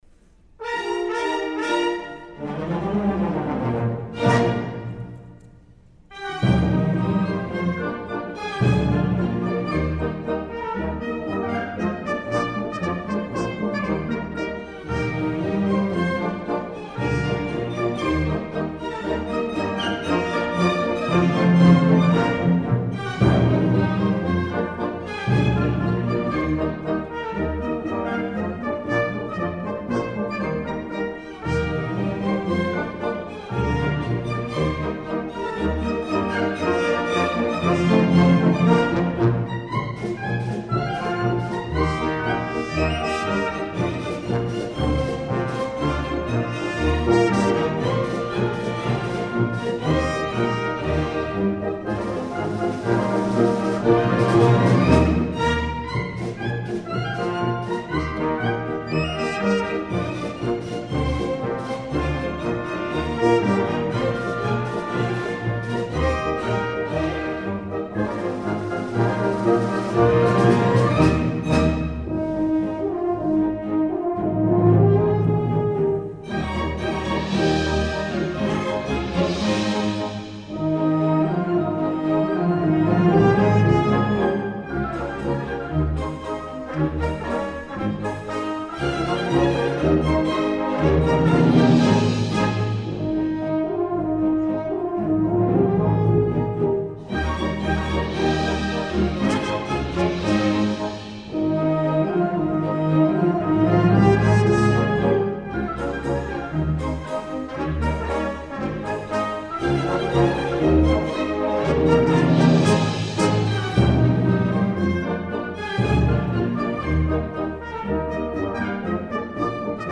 Salut de fleurs (Polka) για Ορχήστρα (live)
Συμφωνική Ορχήστρα Φιλαρμονικής Εταιρείας Κέκρυρας Μαέστρος